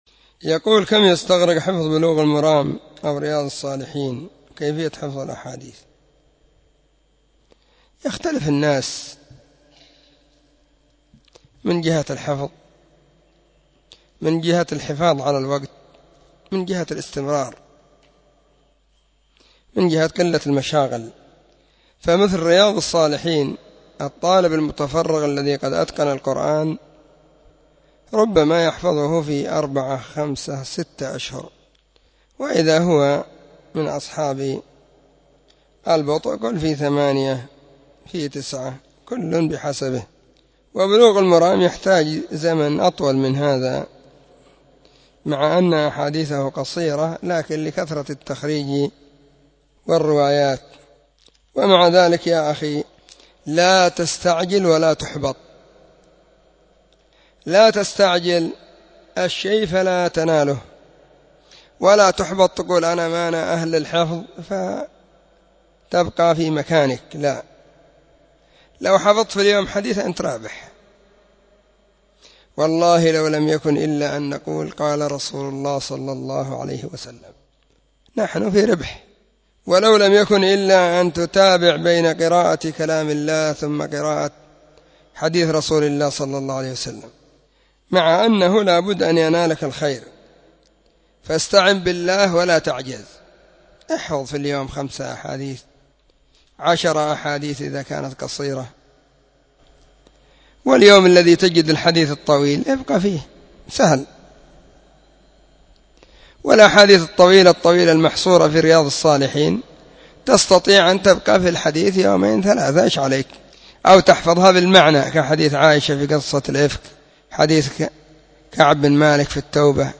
🔸🔹 سلسلة الفتاوى الصوتية المفردة 🔸🔹
📢 مسجد الصحابة – بالغيضة – المهرة، اليمن حرسها الله.